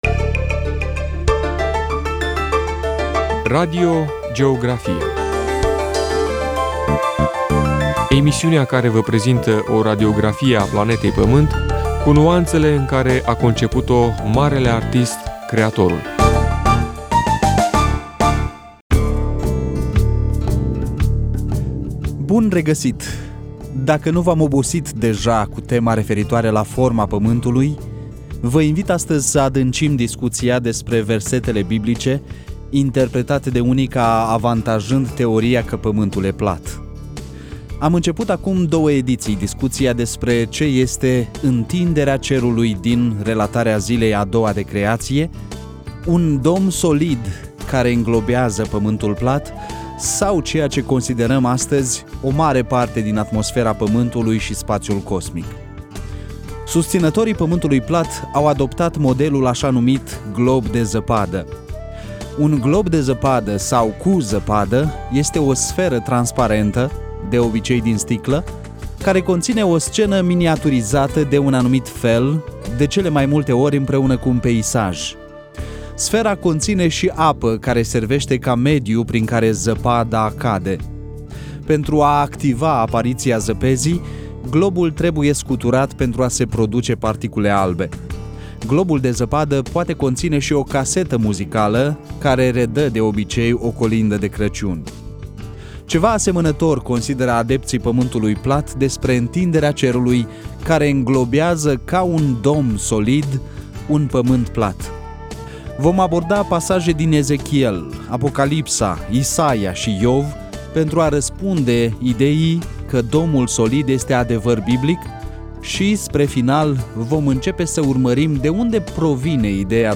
Toate aceste ediţii au fost redate la RVE Suceava în cadrul emisiunii „Radio Georgrafia”.